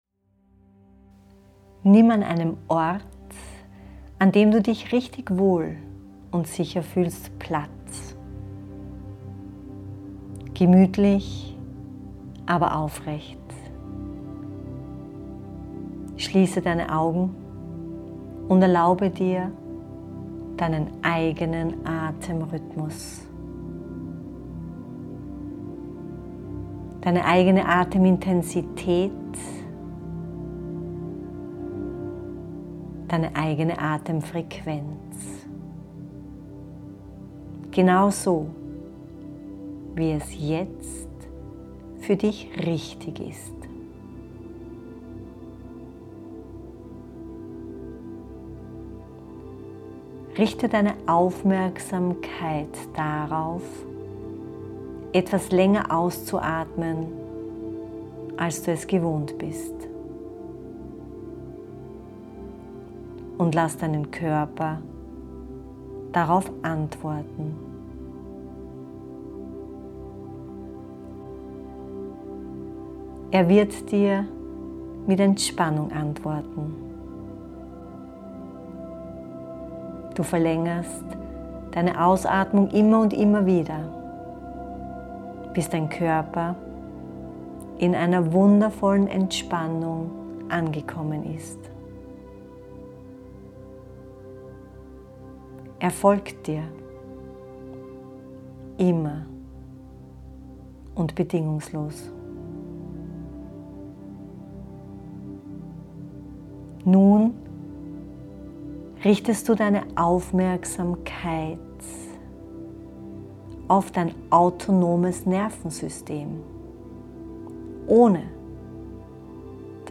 Chill Out Area – Stille und Liebe Wissenschaftlich entspannen : Wie Quantenphysik und Zellbiologie ist im begriff deine Regeneration zu revolutionieren!